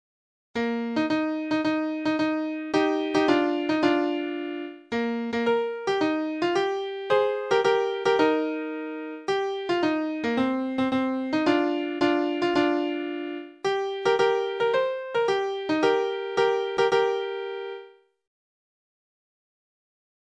ﾋﾟｱﾉﾊﾓ